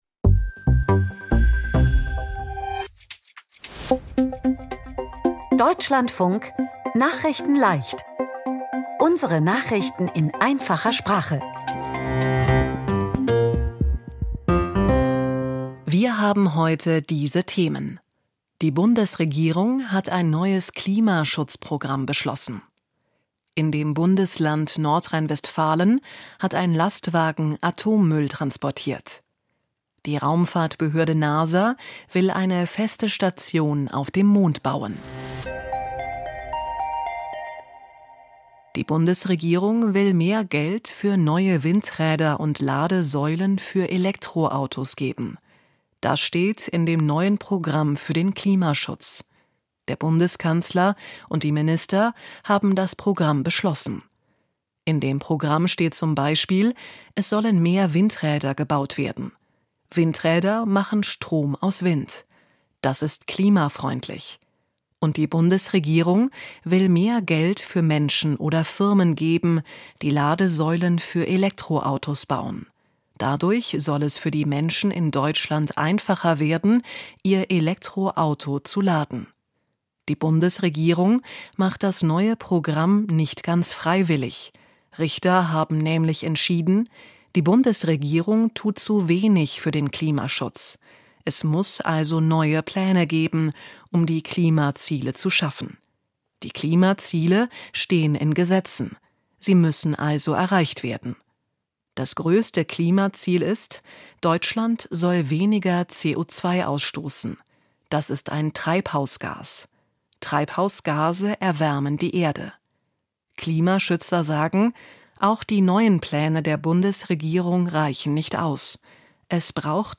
Nachrichten in Einfacher Sprache vom 25.03.2026